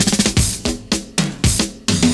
112FILLS07.wav